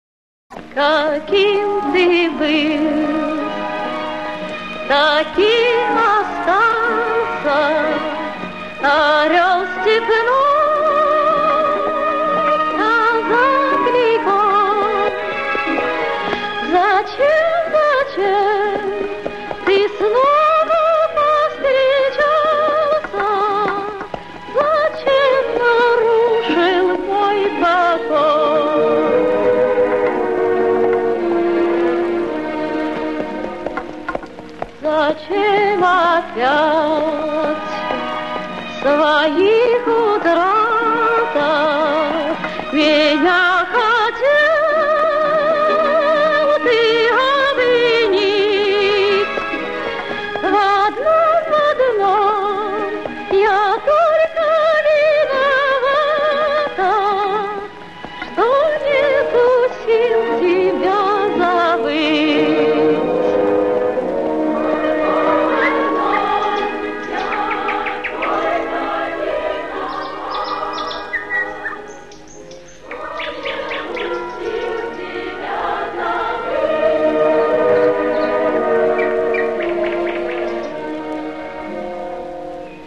Фрагмент песни